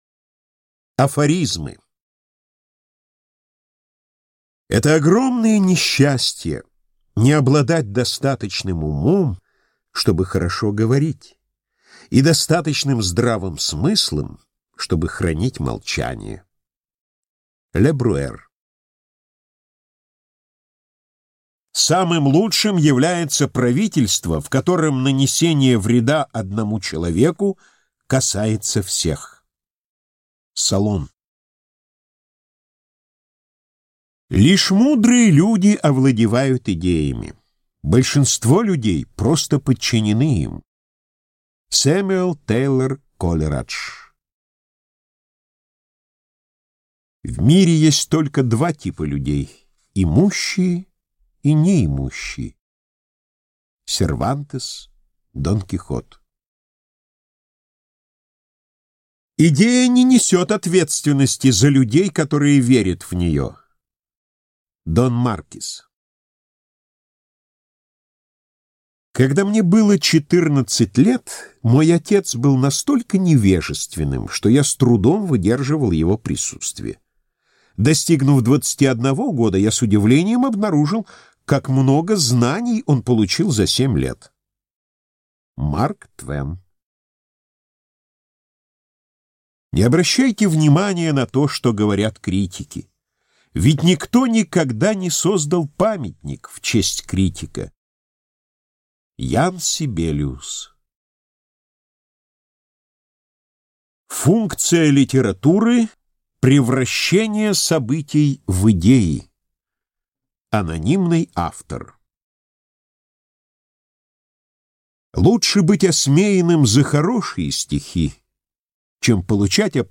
Аудиокнига Весёлые истории и шутки/Funny Stories and Humour | Библиотека аудиокниг